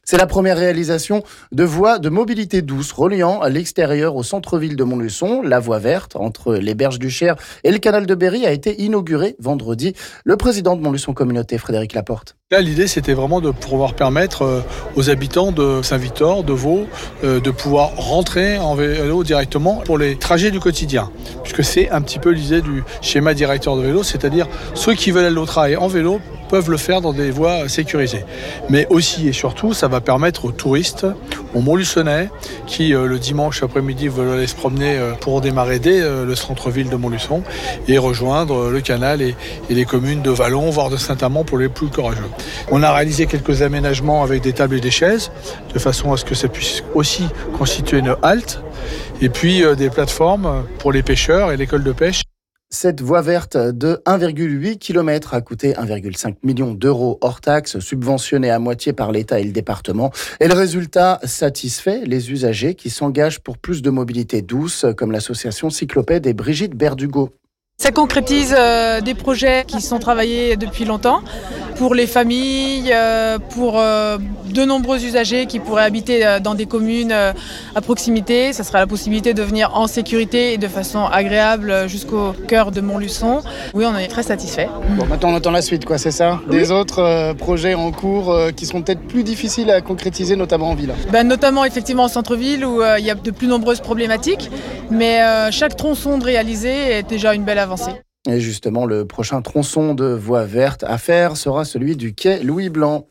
On en parle ici avec le président de Montluçon Communauté et une membre de Cyclopède...